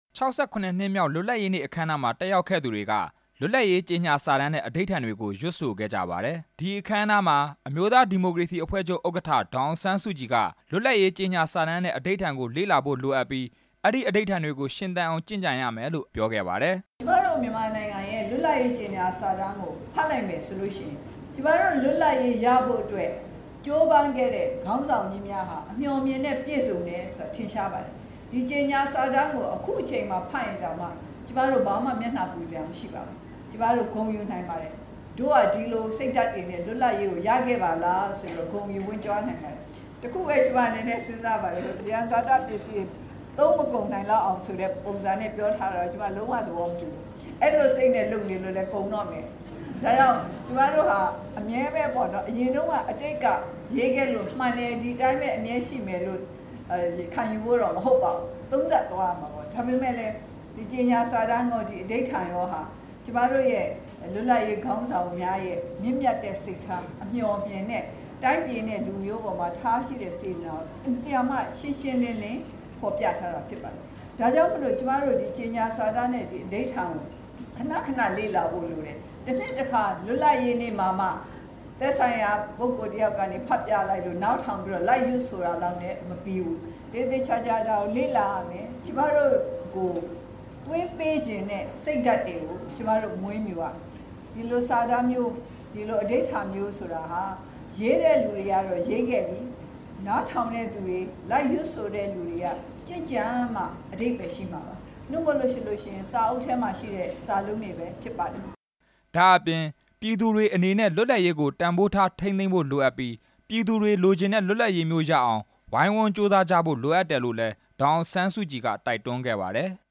dassk-tawwinhinsi-305 ဇန်နဝါရီလ ၄ ရက်နေ့၊ ၆၇ နှစ်မြောက် လွတ်လပ်ရေးနေ့ အခမ်းအနားမှာ ဒေါ်အောင်ဆန်းစုကြည် မိန့်ခွန်းပြောကြား စဉ်
အမျိုးသားဒီမိုကရေစီအဖွဲ့ချုပ်က ရန်ကုန်မြို့ တော် ဝင်နှင်းဆီခန်းမမှာကျင်းပတဲ့ လွတ်လပ်ရေးနေ့ အခမ်းအနားမှာ ဒေါ်အောင်ဆန်းစုကြည်က အခုလိုပြောကြားသွားတာဖြစ်ပါတယ်။